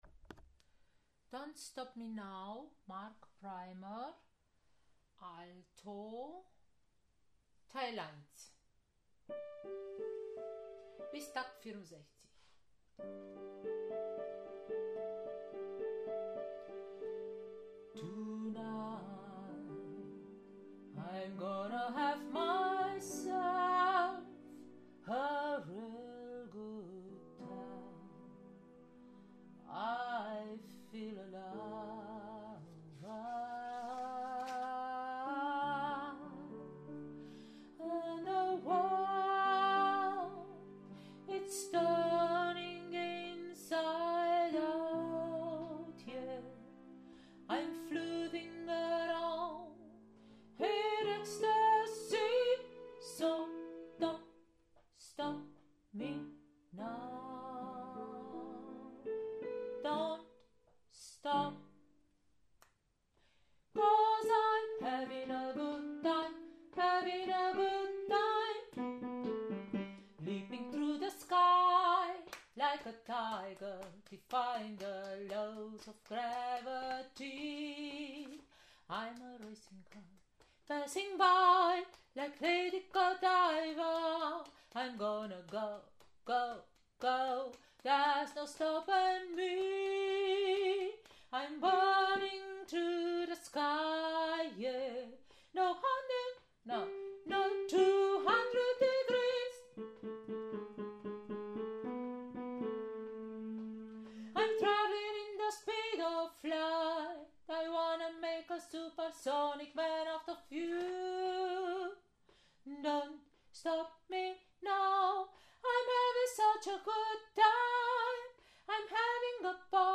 Don’t stop me now Alto Teil 1